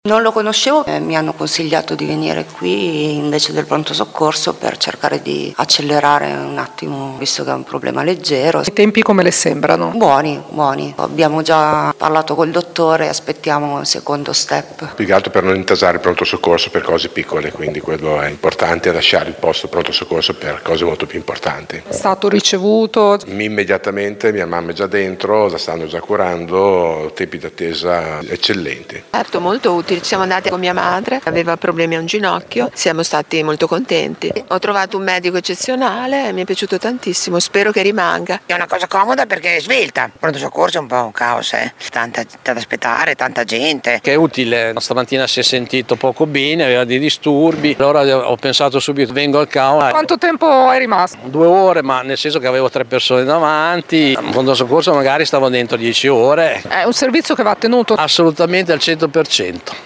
Siamo quindi andati a vedere di persona come funzionano le cosa al Cua del Policlinico di Modena:
VOX-CAU.mp3